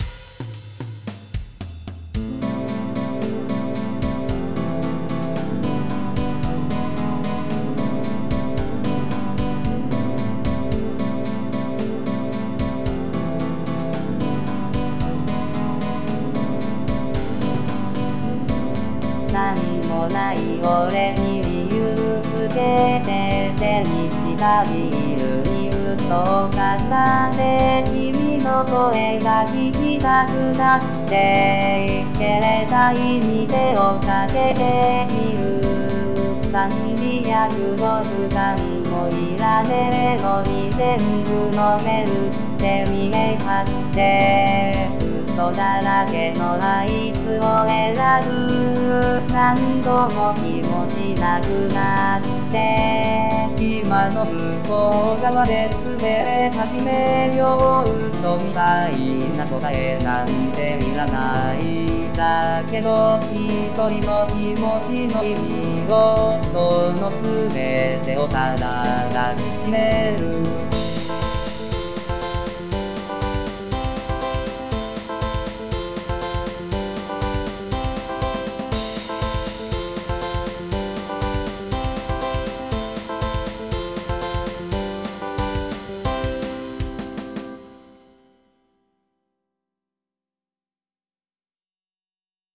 日本語歌詞から作曲し、伴奏つき合成音声で歌います。